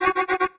pgs/Assets/Audio/Alarms_Beeps_Siren/alarm_siren_warning_01.wav at master
alarm_siren_warning_01.wav